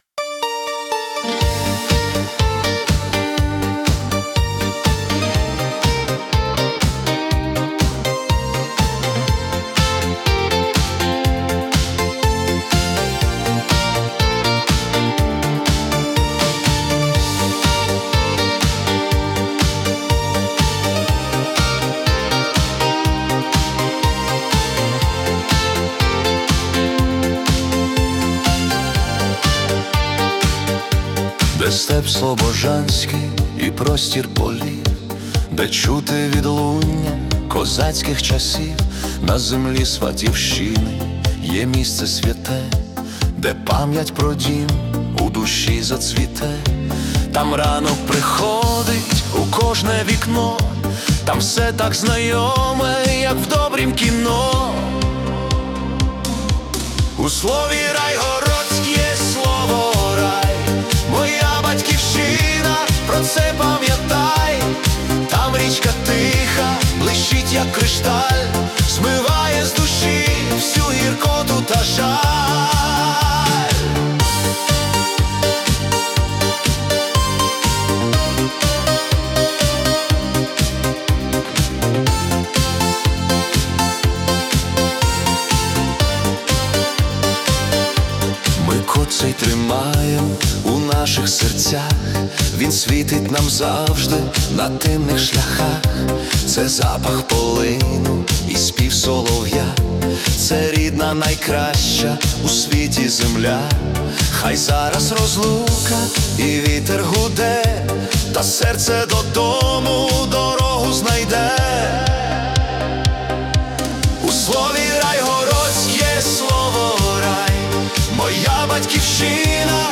🎵 Жанр: Italo Disco / Nostalgic